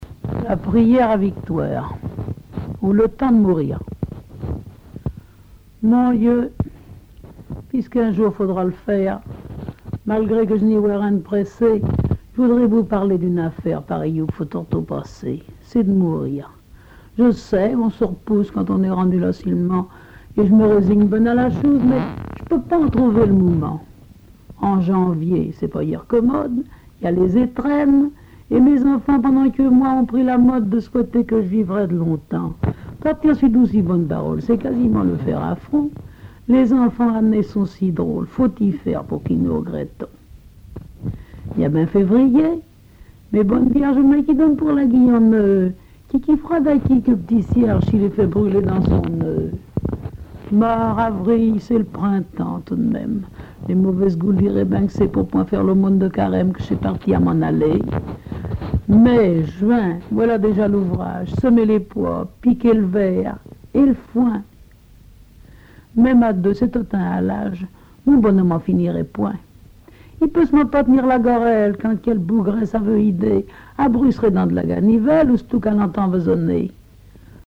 Genre récit
Récits et chansons en patois